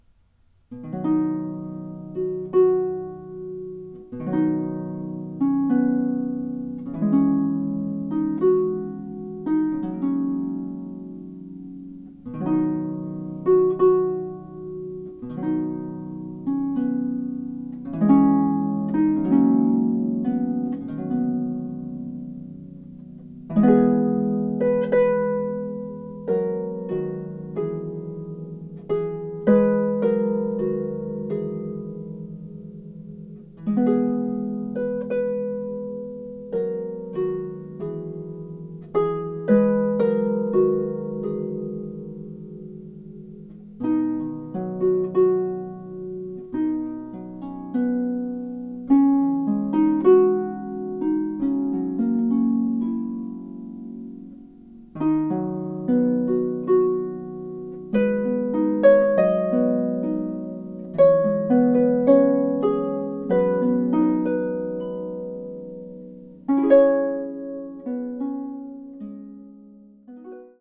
Harpist